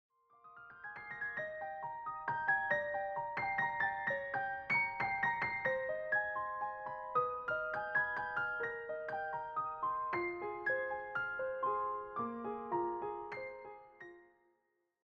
all rendered as solo piano pieces.
intimate, late-night atmosphere